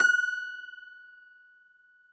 53r-pno20-F4.aif